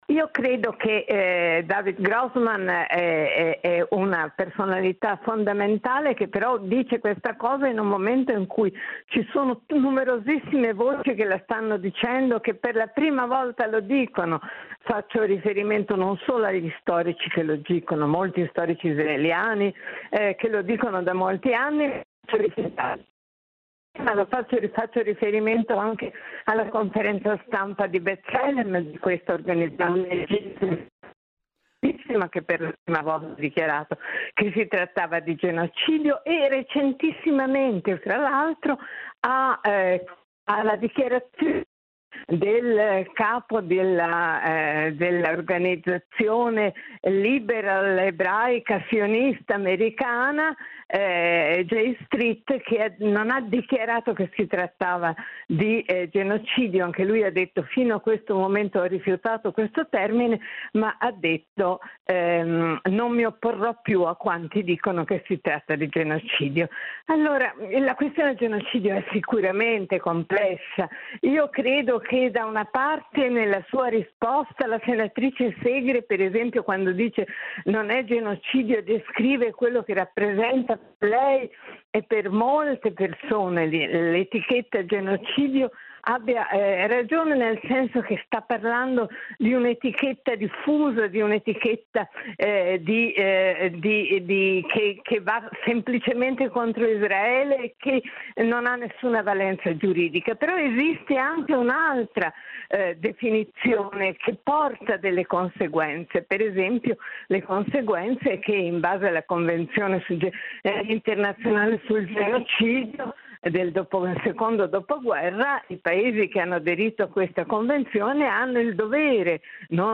Alla nostra trasmissione Summertime è stata ospite la storica Anna Foa, che ha sottolineato la necessità che aumenti sempre più un’opposizione all’esecutivo di Tel Aviv, con la rinascita di una resistenza morale.